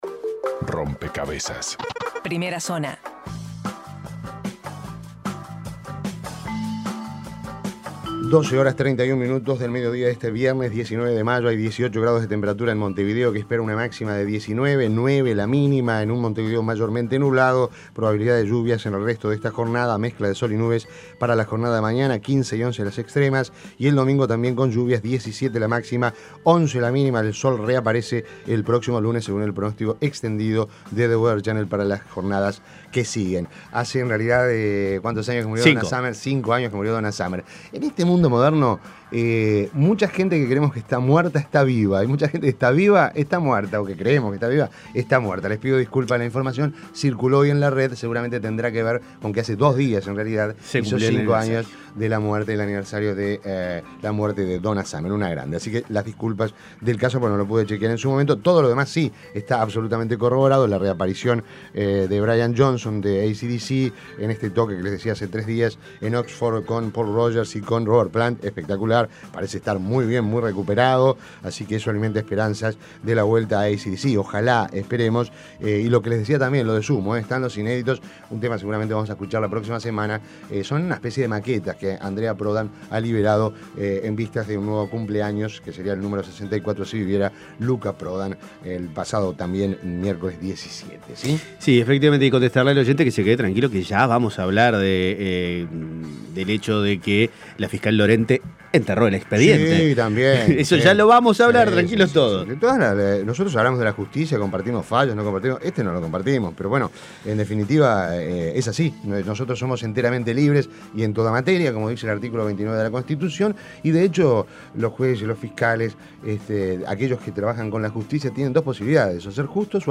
Resumen de noticias Primera Zona Nacional Imprimir A- A A+ Las principales noticias nacionales, resumidas en la Primera Zona de Rompkbzas.